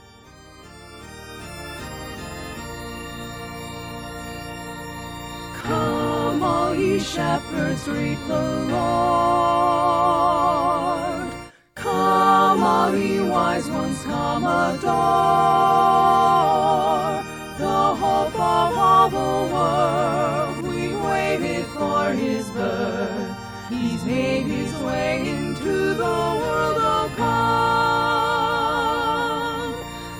choral song arrangements